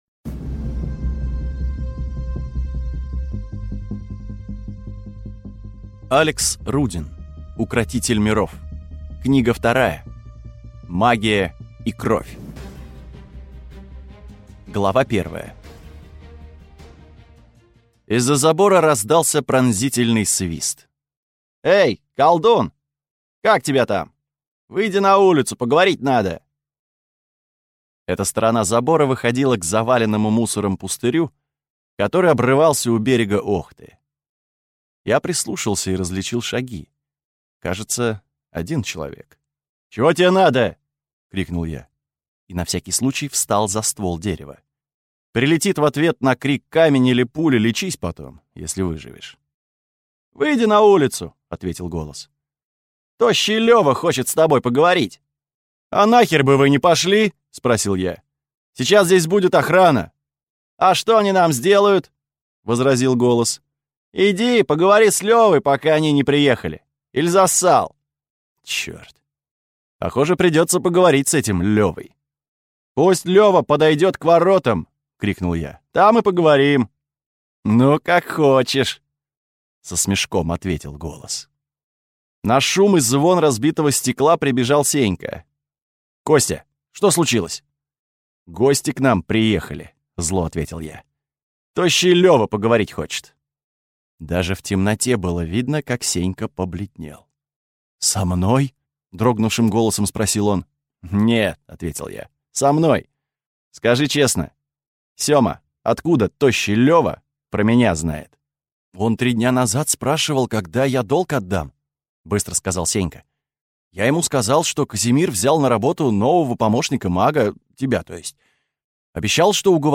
Аудиокнига. Колдовские твари, оборотни в погонах и кровожадные аристократы старались меня убить.